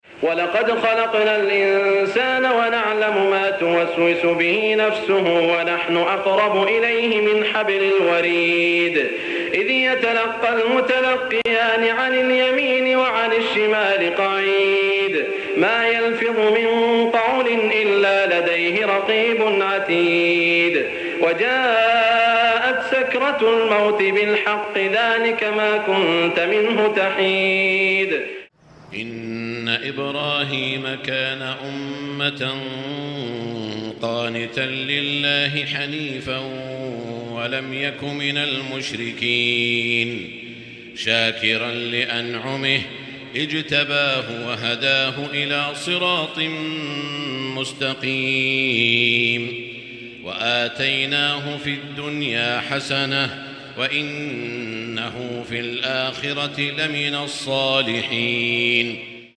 Beautiful Recitation of the Quran
📍 Masjid Al Haram, Makkah, Saudi Arabia